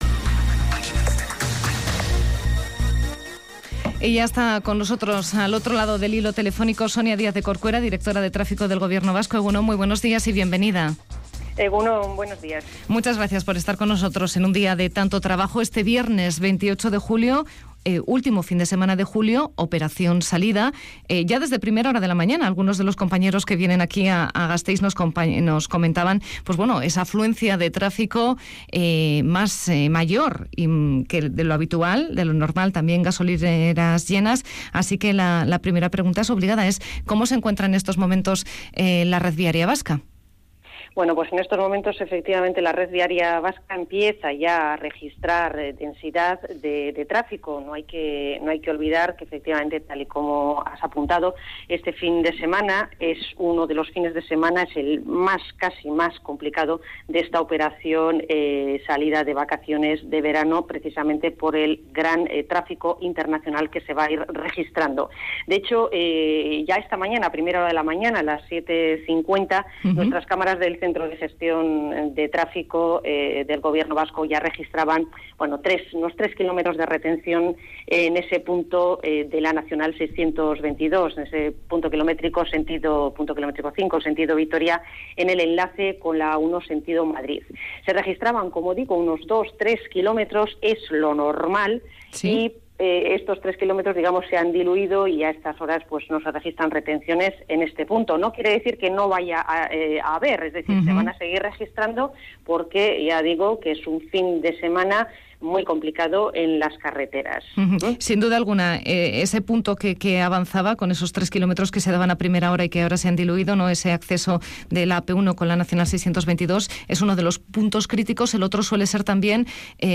La directora de trafico del Gobierno Vasco en Araba Gaur de Radio Vitoria
Sonia Diaz Corcuera ha señalado en la primera edición de Araba Gaur, que este fin de semana será uno de los más complicados del verano en la red viaria vasca.